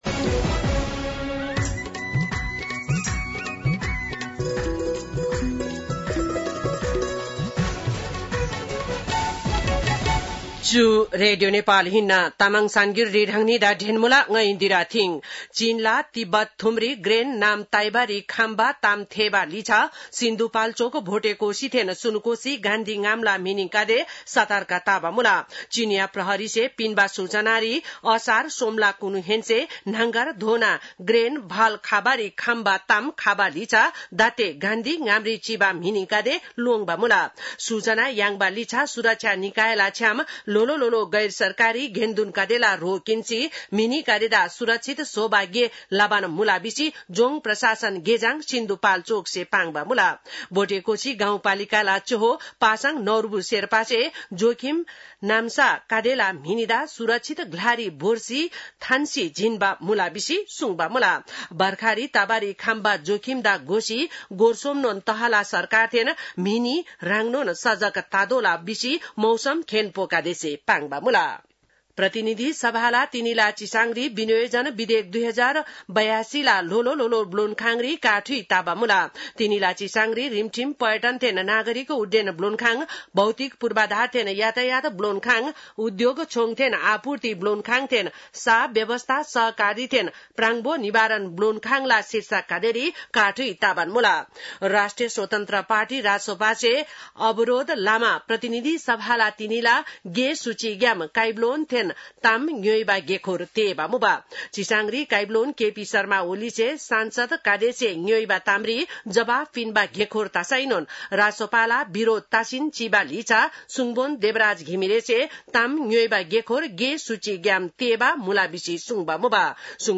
तामाङ भाषाको समाचार : ६ असार , २०८२